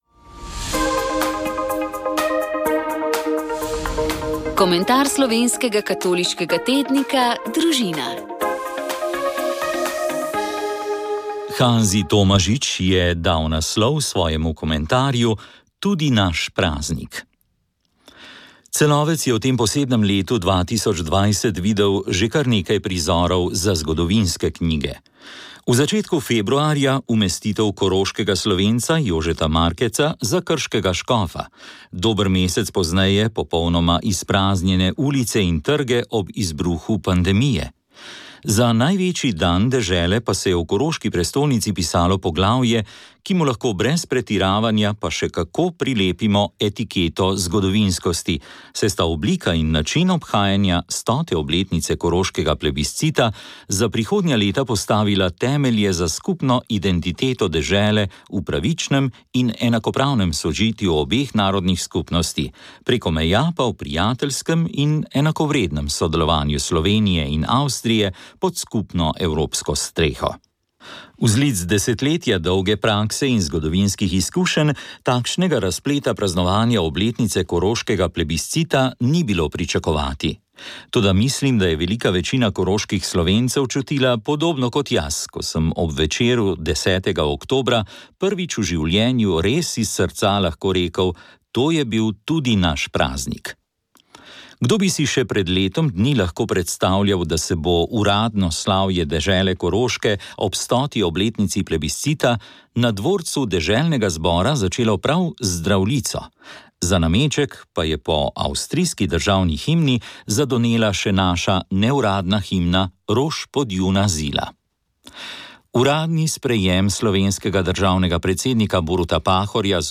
O digitalizaciji je na letošnjem Novoletnem srečanju v Tinjah na avstrijskem Koroškem razmišljal